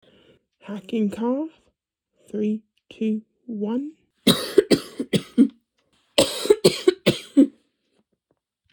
Download Hacking sound effect for free.